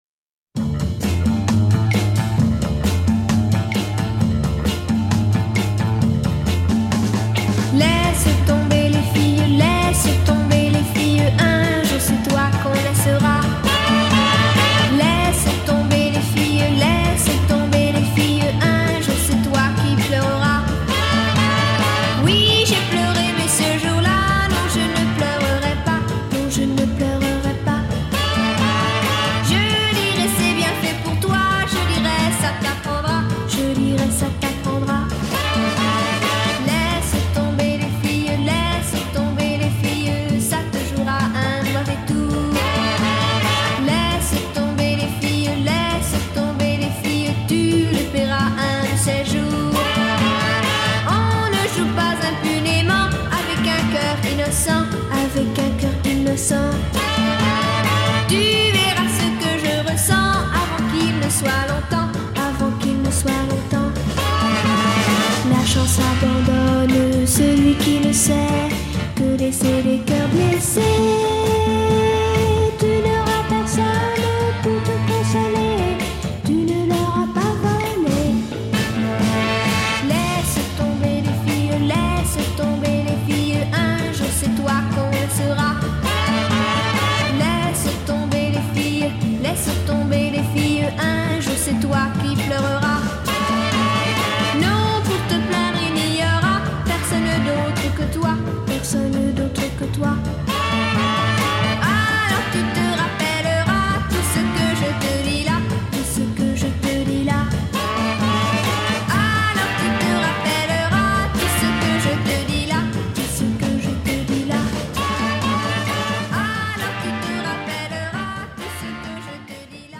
It’s fun and bouncy and perfect for a lovely sunny day.